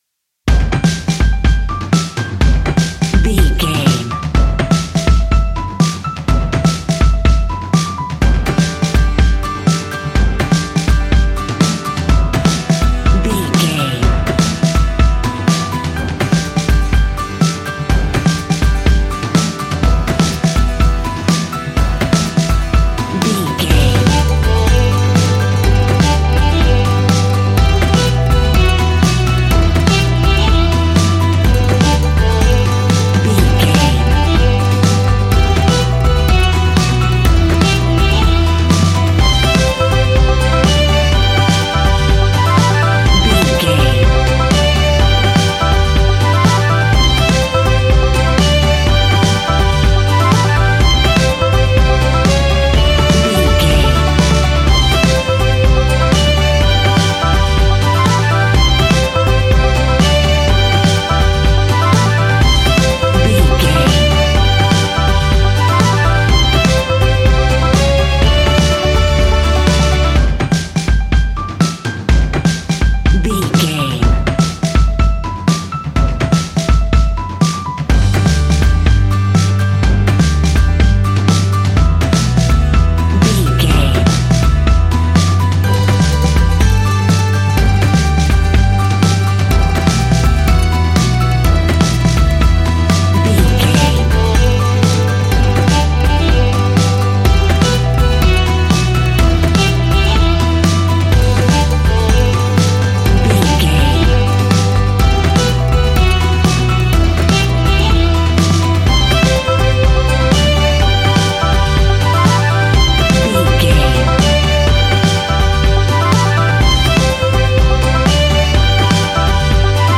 Ionian/Major
Fast
acoustic guitar
mandolin
accordion